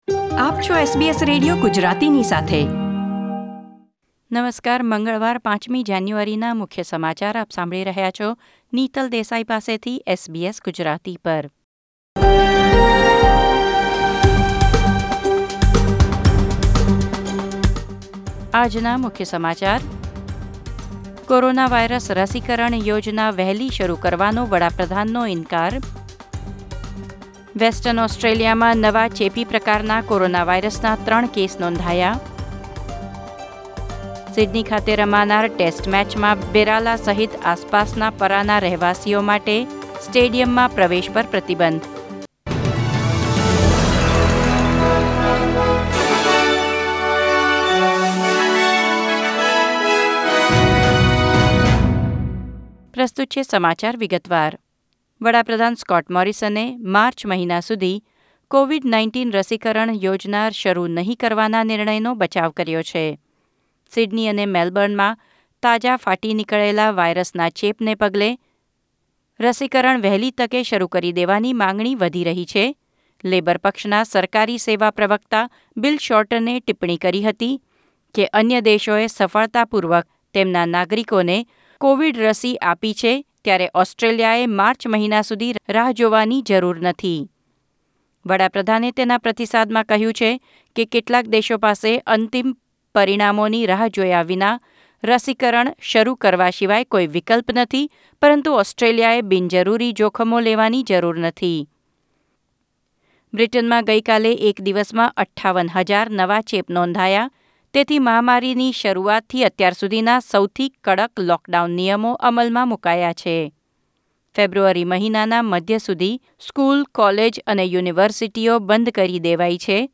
SBS Gujarati News Bulletin 5 January 2021